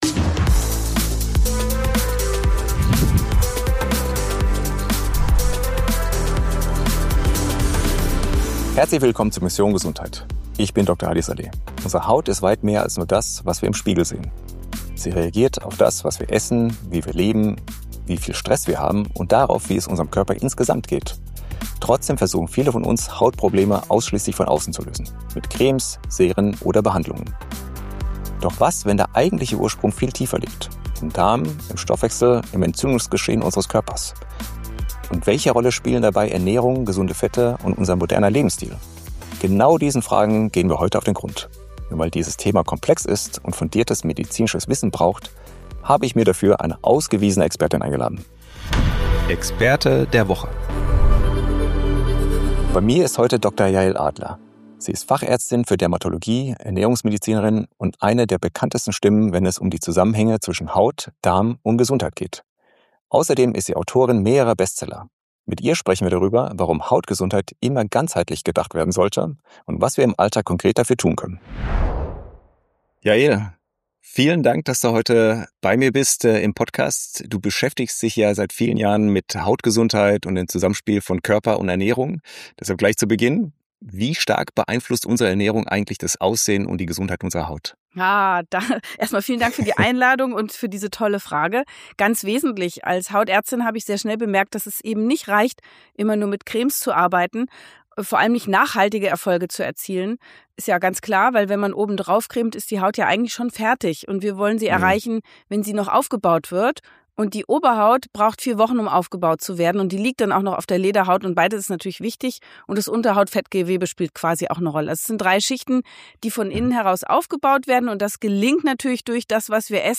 Gemeinsam mit der Dermatologin, Ernährungsmedizinerin und Besteller Autorin Dr. Yael Adler spreche ich darüber, wie Ernährung, Darmgesundheit und stille Entzündungen das Hautbild beeinflussen – und warum Cremes allein selten die Lösung sind. Wir schauen auf die Rolle von Omega 3, gesunden Fetten, den Einfluss von Stress und Hormonen und darauf, was unsere Haut uns über unseren Lebensstil sagen kann.